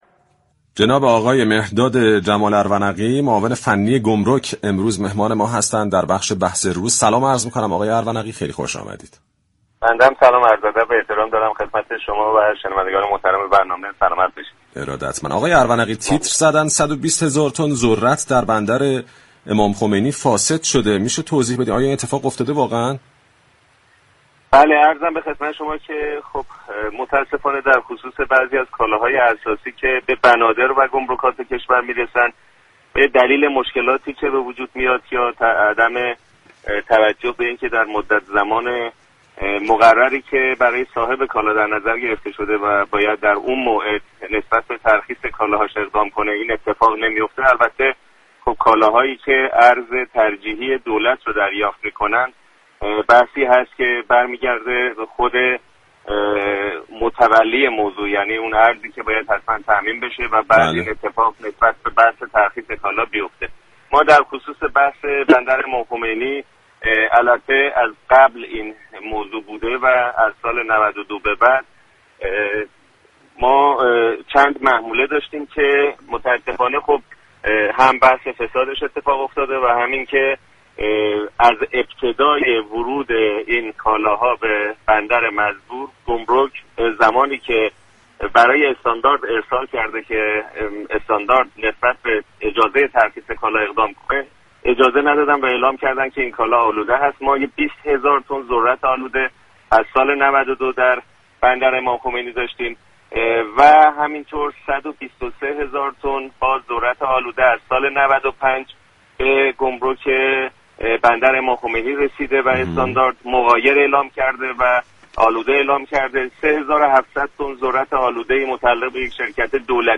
به گزارش پایگاه اطلاع رسانی رادیو تهران، مهرداد جمال ارونقی معاون فنی گمرك كشور در گفتگو با برنامه بازار تهران با تایید فساد 120 هزار تن ذرت در بندر امام خمینی(ره) گفت: متاسفانه برخی كالاهای اساسی كه ارز ترجیحی را دریافت می‌كنند به دلیل پاره‌ای از مشكلات و عدم توجه به زمان مقرری كه برای ترخیص كالاها در نظر گرفته شده است در بنادر می‌مانند.